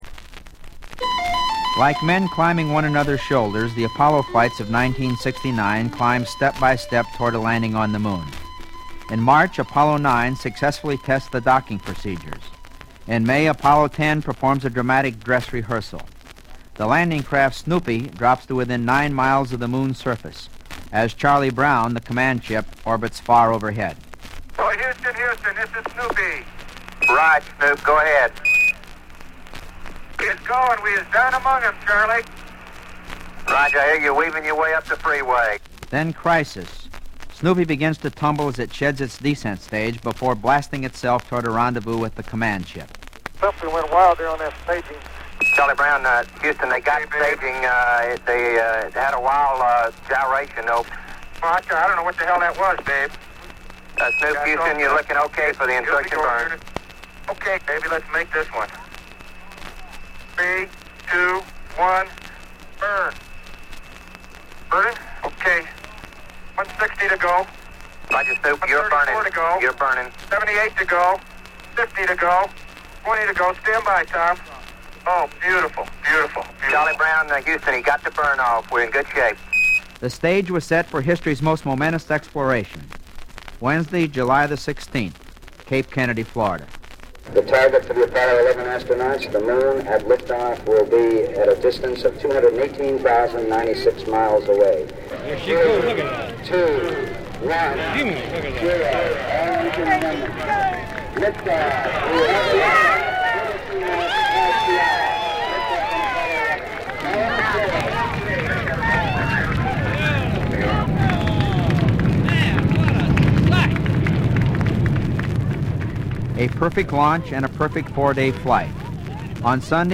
Also in the record jacket, when I bought the record at a used book sale, I also found a copy of "Sounds of the Space Age", narrated by Col. Frank Borman, one of those bendy records that they used to sometimes put in books, which seems to have come in the December 1969 edition of National Geographic.